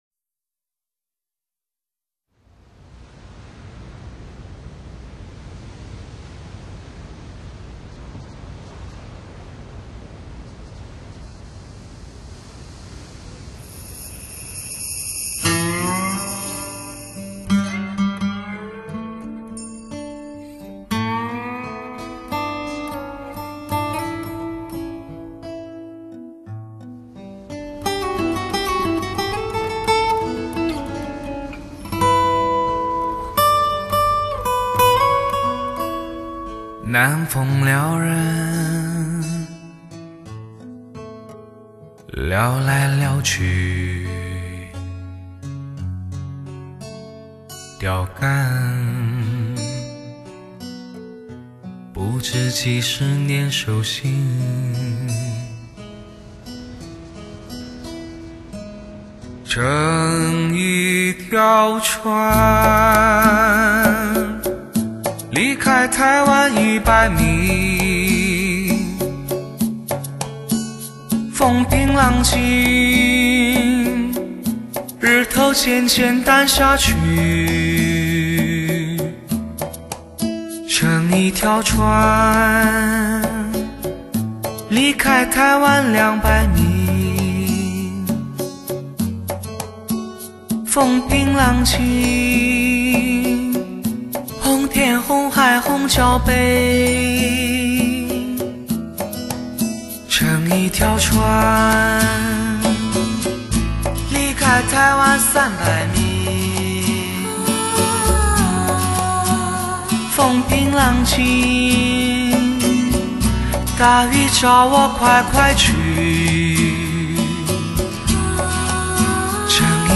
包罗万象的忧伤，喜悦热烈，奔放尽显其中。